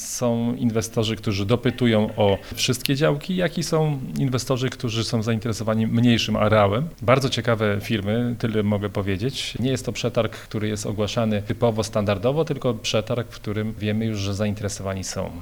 Na tym jednak nie koniec dobrych wieści dla mieszkańców Goleniowa i okolic, bo w najbliższych dniach mają się odbyć kolejne przetargi na grunty pod inwestycje, a zainteresowanie nimi – jak informuje wiceburmistrz Tomasz Banach – widać spore.